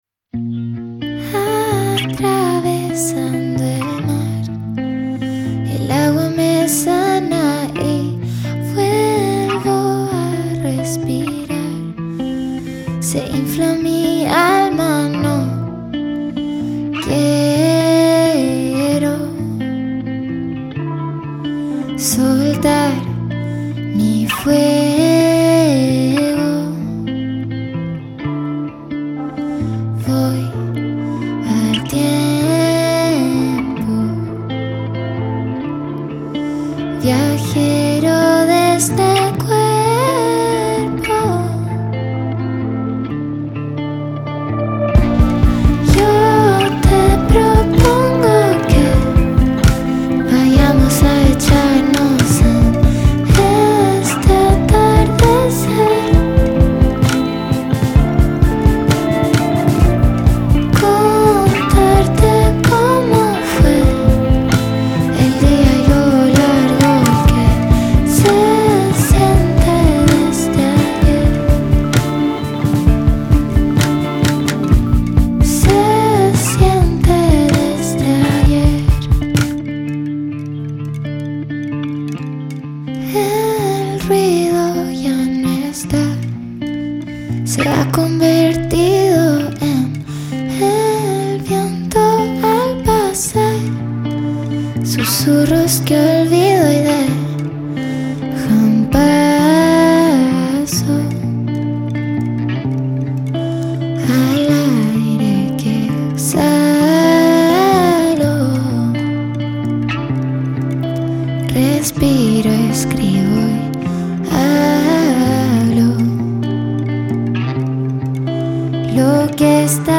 fusiona la sensibilidad pop con la madurez de la experiencia
álbum de estudio
Acercándose a veredas más alternativas
tiene ese velo angustioso de saber que hay que dejar ir